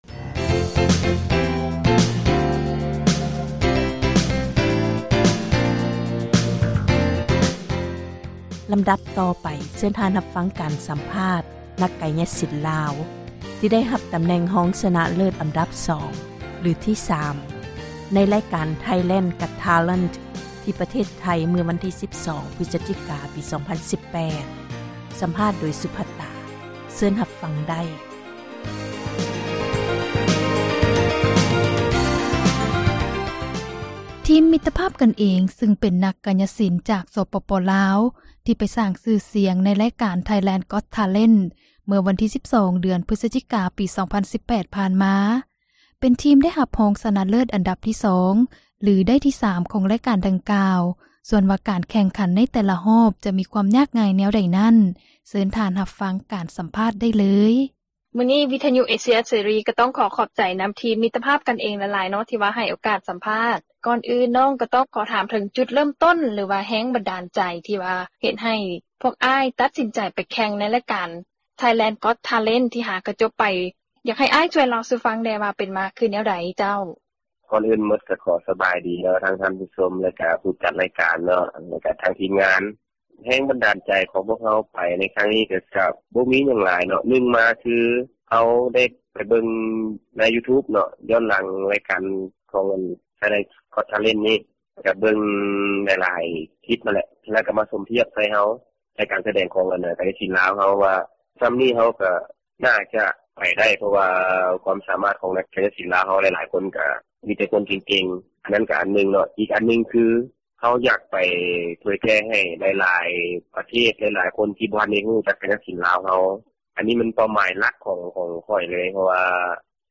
ສັມພາດ ນັກກາຍະສິນ ລາວ